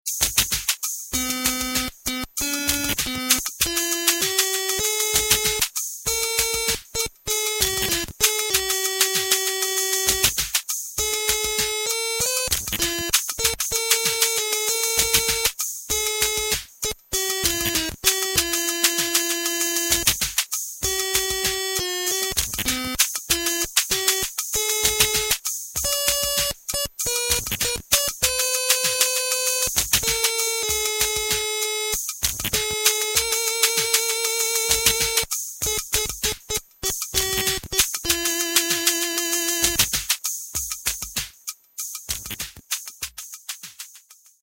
Stylophoneで演奏してみるの巻
StylophoneにはLINE IN/OUTがあるので、手持ちの KORG KAOSSILATOR → Stylophone → MP3ラジカセ という風につないでみる。 バックはKAOSSILATORで適当につくることにする。
スタイラスをスライドさせながら弾くのを組み合わせると、わりときれいにいくような気がする。